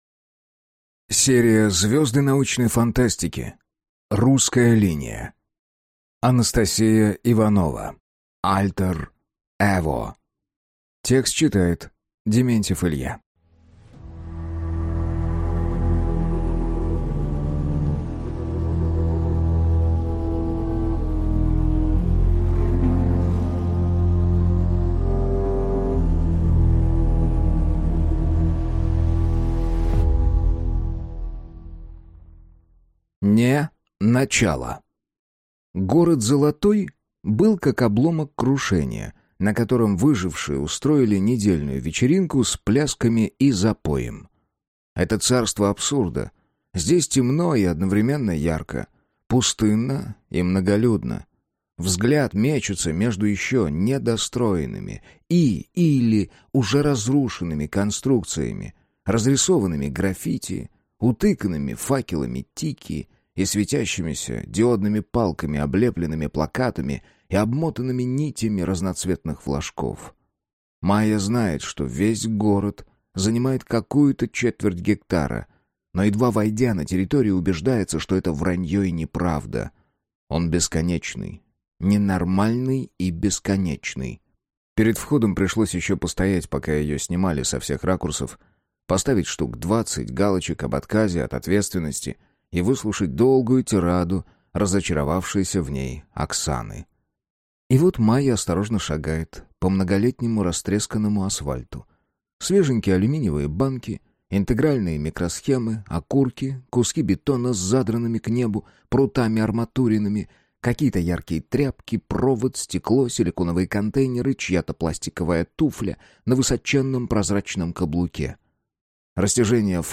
Аудиокнига Альтер эво | Библиотека аудиокниг